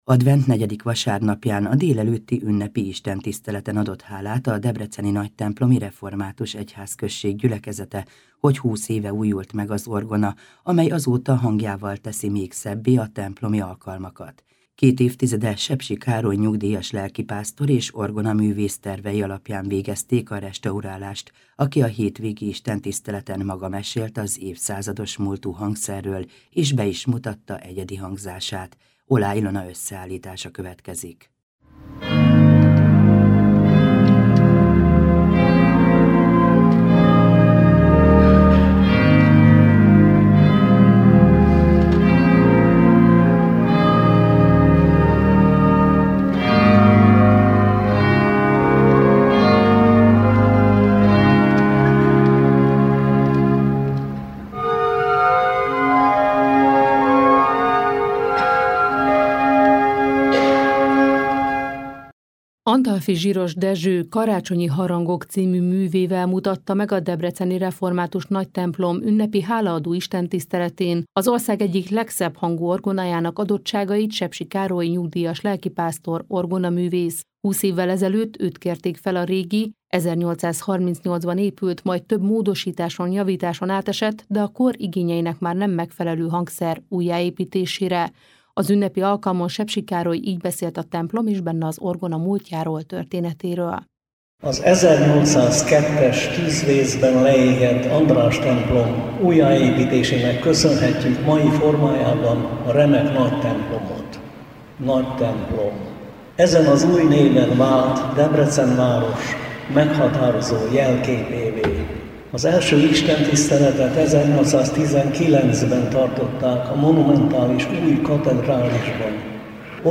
Hálaadó istentisztelet a Nagytemplomban: 20 éve szól a megújult orgona - hanganyaggal
2016. december 18-án emlékeztünk meg ünnepélyesen a 10 órakor kezdődő istentiszteleten a két évtizede történt munkálatokról.
1221-nagytemplomi-orgona.mp3